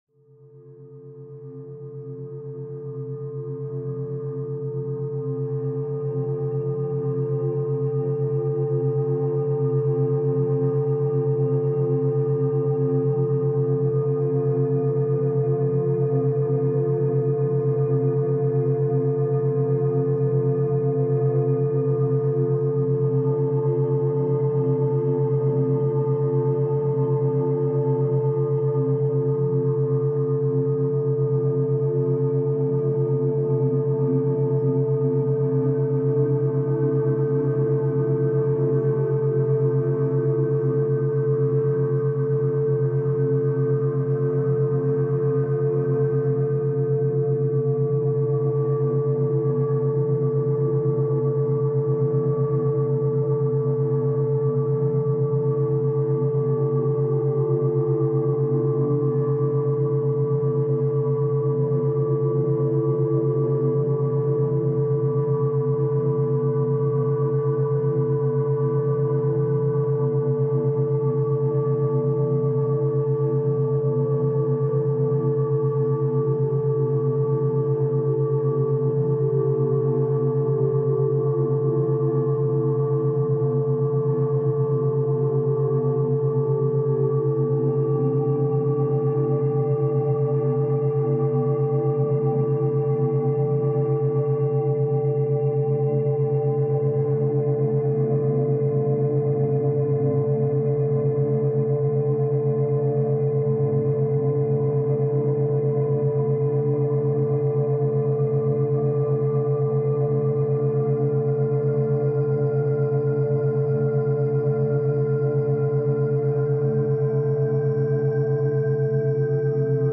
ストレス解消 – デルタ波＆432 Hz | 完全なリラクゼーション | バイノーラルビート瞑想